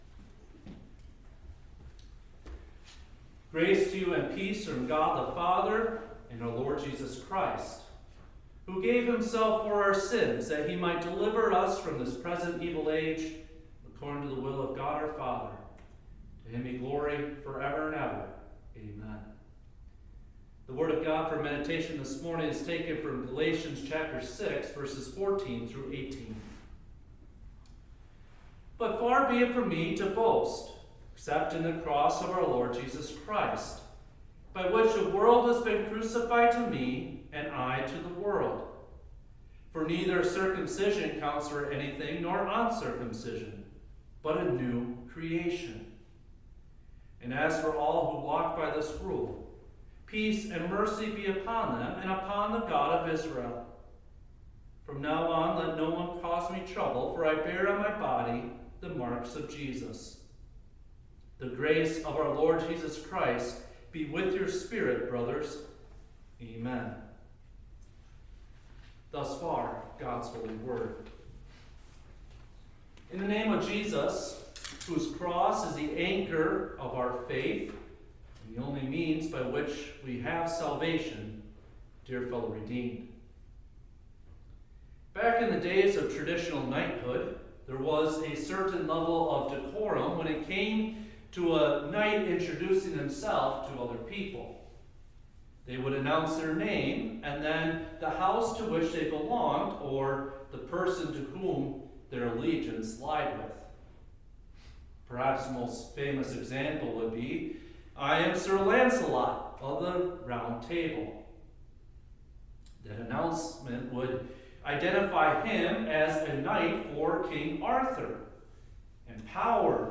Holy-Cross-Sunday.mp3